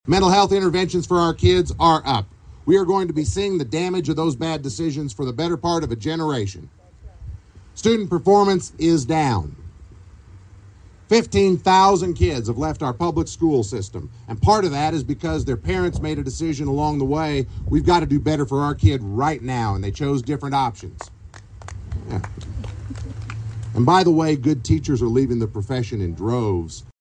Republican gubernatorial candidate Derek Schmidt headlined a Kansas GOP bus tour stop at the Lyon County Fairgrounds alongside US House Second District candidate Jake LaTurner and US Senator Roger Marshall on Saturday.